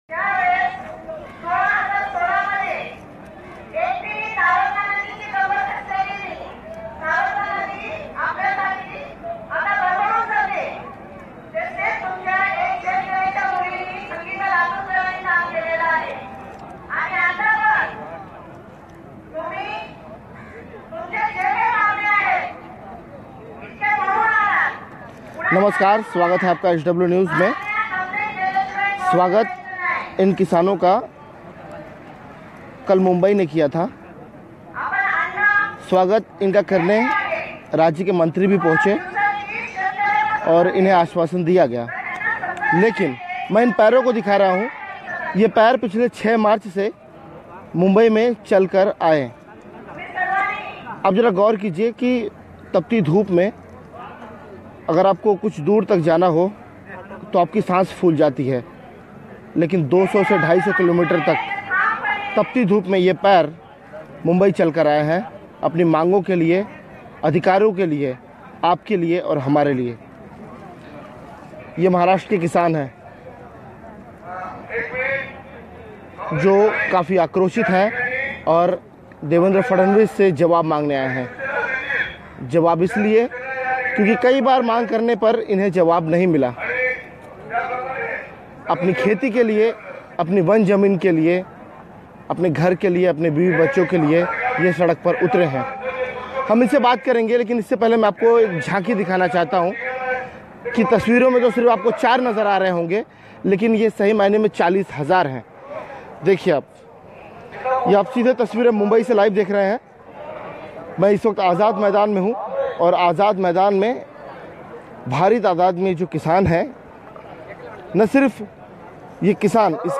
News Report / पैर फटे, रिस रहा खून, फिर भी नंगे पैर मुंबई आए 40 हजार किसान